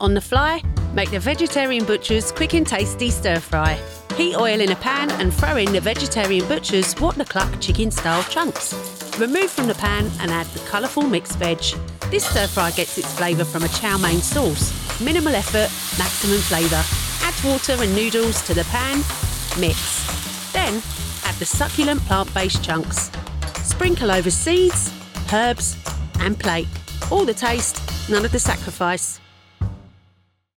From warm, smooth and classy to fresh, upbeat and natural.
The Vegetarian Butcher Commercial (London Accent)
Middle Aged
I have my own professional home studio and can deliver a fast turnaround between 24-48 hours.